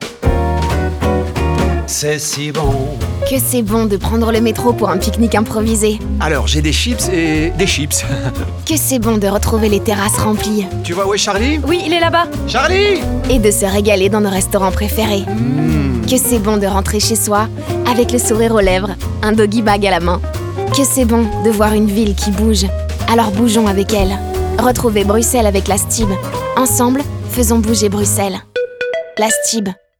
Spot radio 2